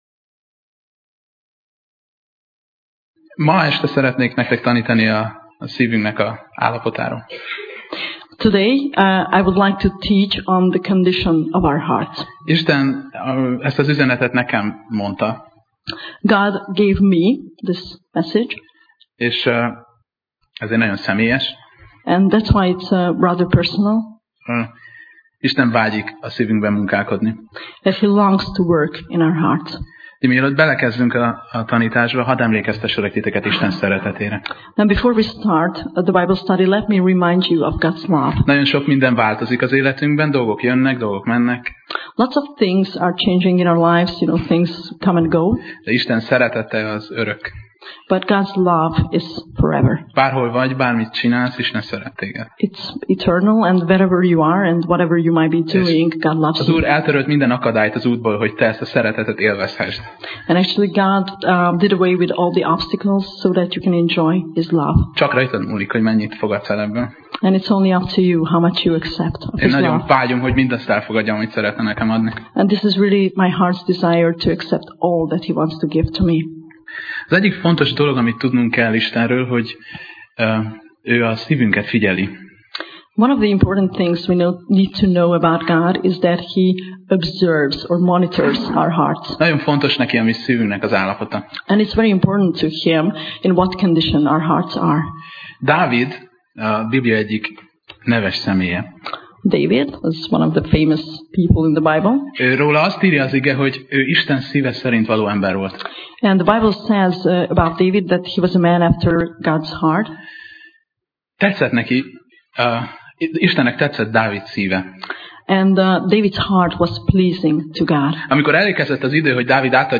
Sorozat: Tematikus tanítás Alkalom: Szerda Este